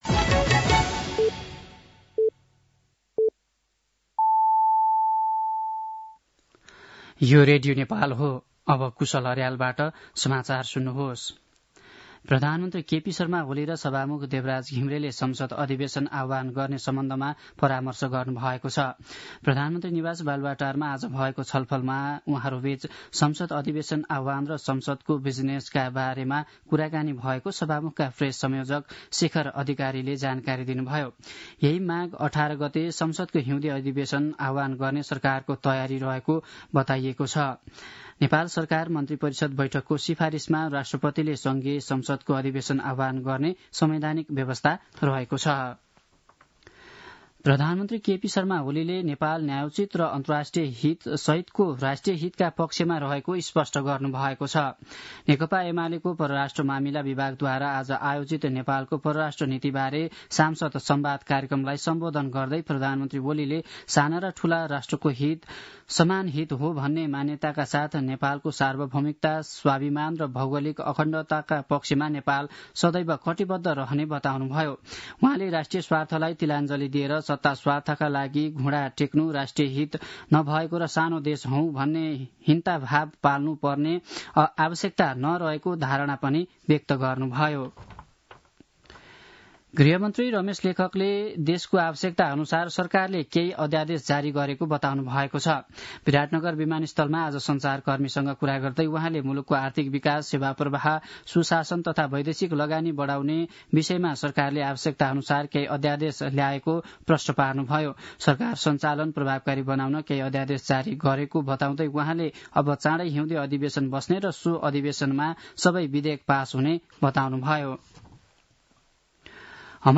साँझ ५ बजेको नेपाली समाचार : ८ माघ , २०८१
5-pm-nepali-news-10-07.mp3